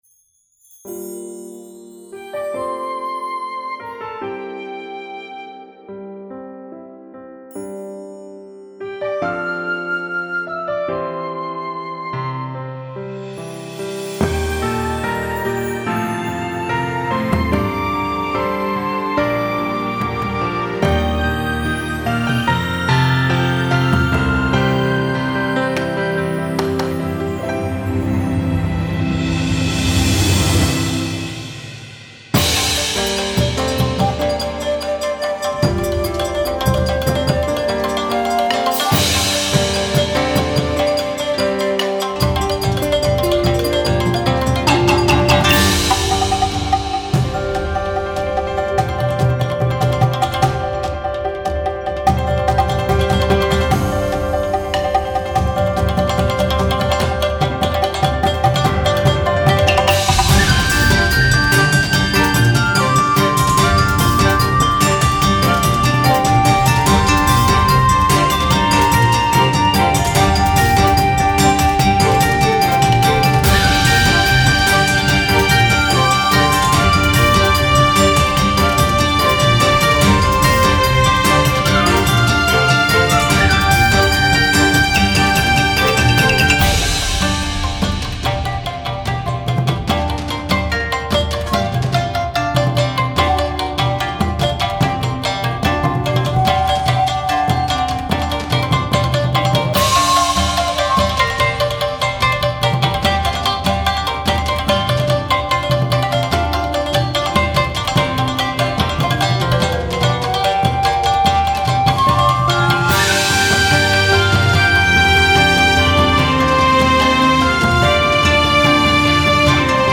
琴や三味線、太鼓、尺八などを用いた和風のBGMです。